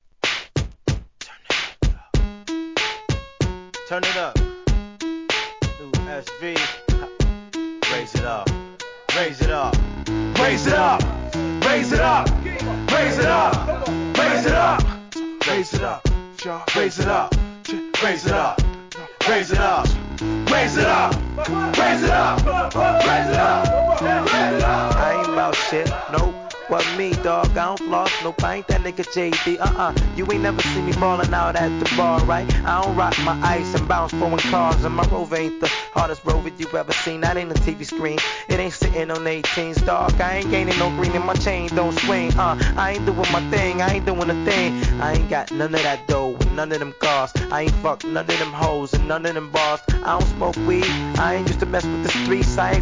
HIP HOP/R&B
タイトルを連呼するフックが印象的な2000年リリース!